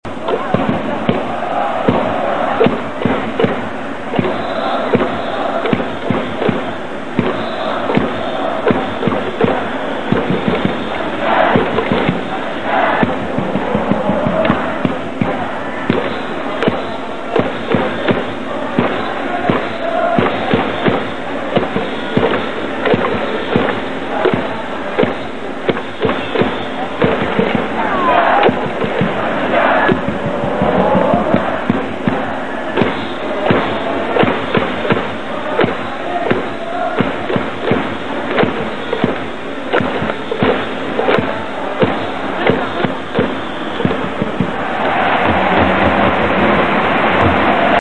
東北楽天ゴールデンイーグルス選手応援歌